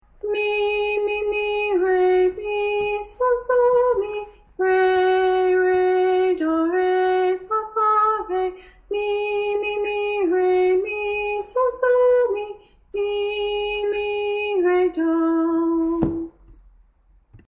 It doesn’t go as high.
Let’s sing the whole thing in solfa: